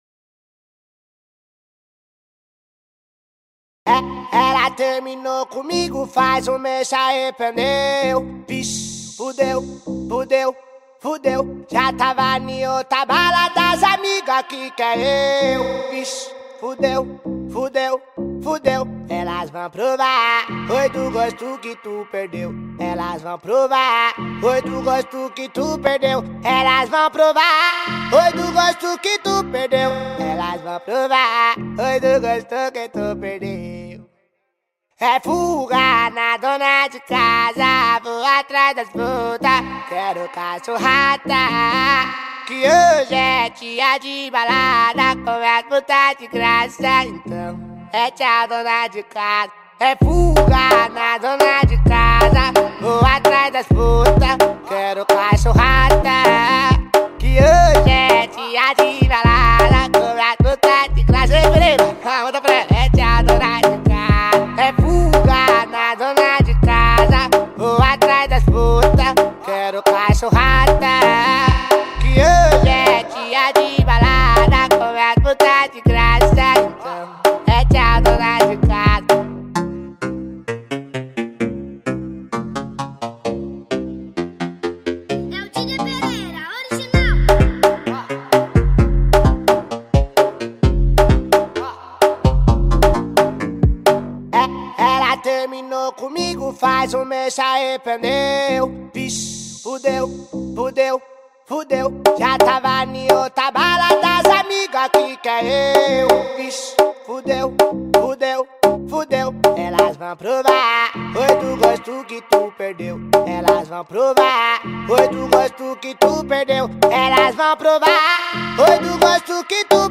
2025-01-02 20:37:18 Gênero: Funk Views